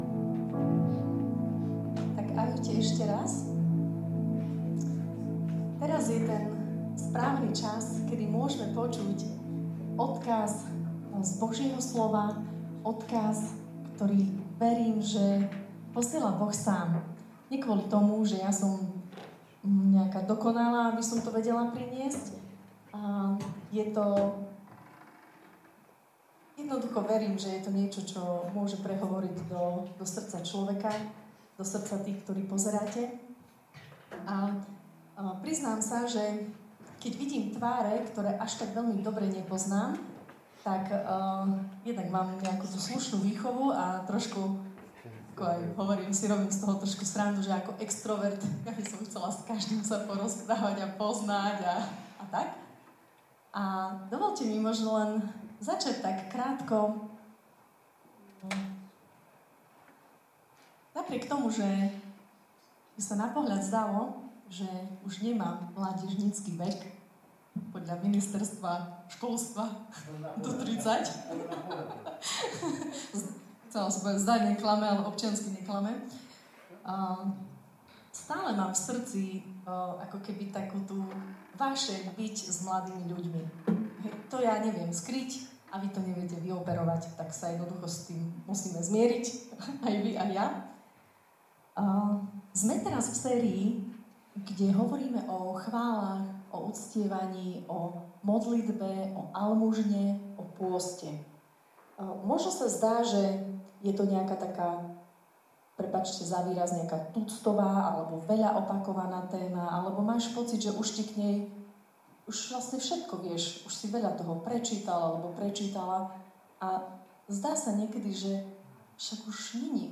Audio kázeň